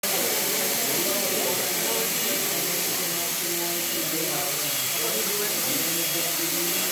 Power tools.mp4